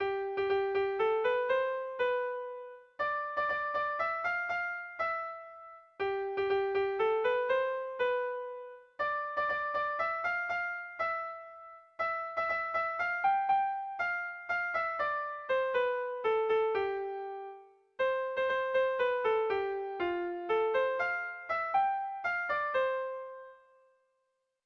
Dantzakoa
Fandango (Trikitia)